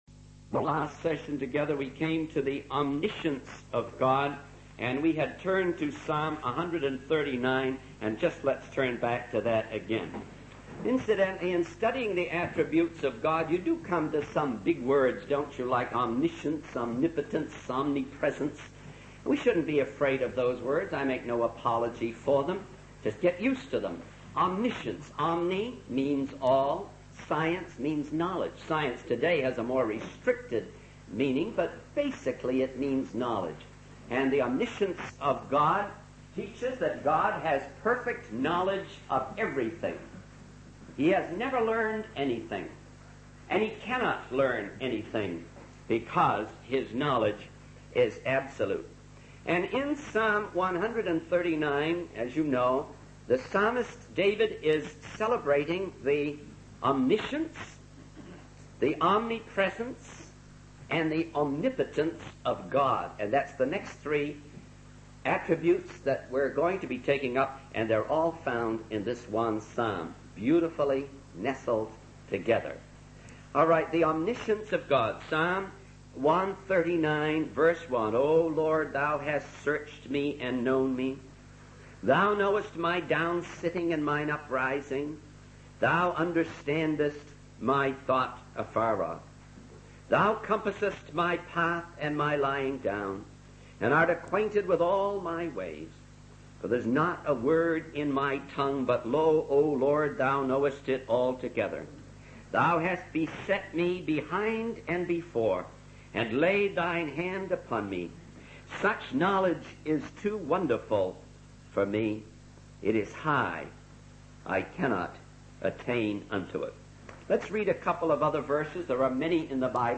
In this sermon, the speaker emphasizes that we are defenseless and surrounded by enemies, such as the Hittites, Hivites, Amorites, Canaanites, and Philistines.